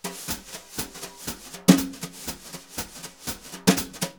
Index of /90_sSampleCDs/Univers Sons - Jazzistic CD 1 & 2/VOL-1/03-180 BRUSH